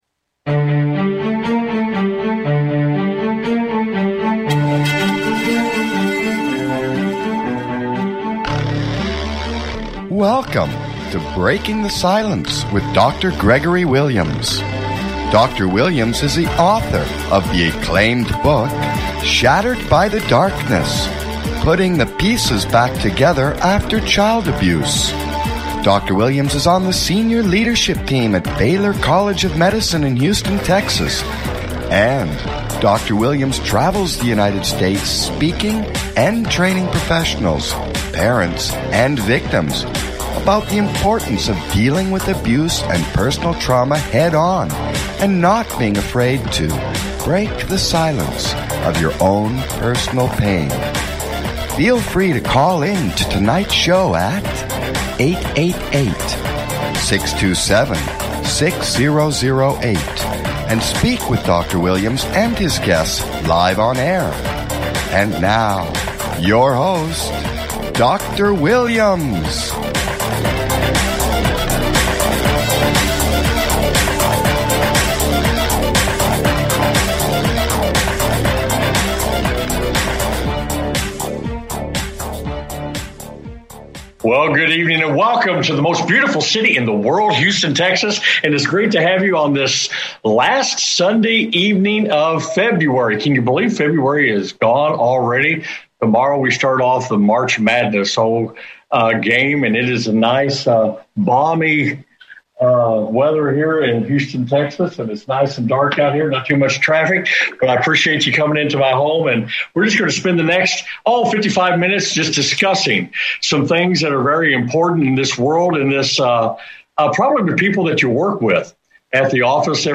Breaking the Silence Talk Show